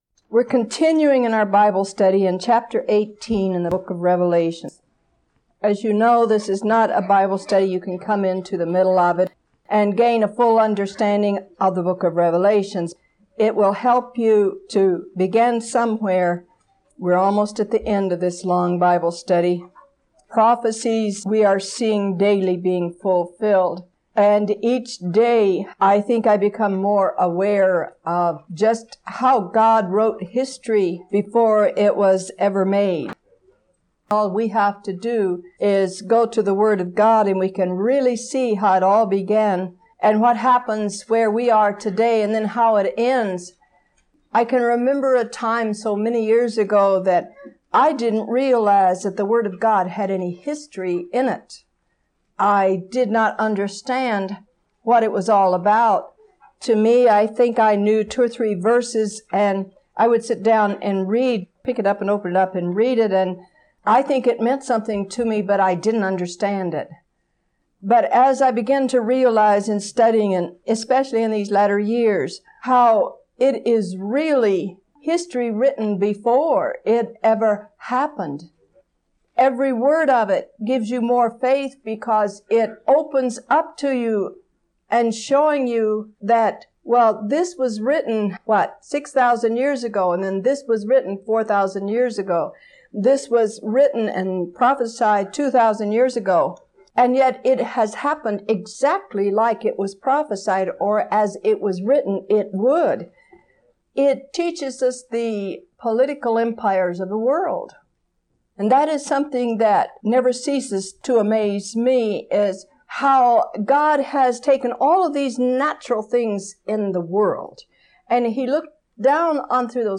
February 11, 1987 – Teaching 57 of 73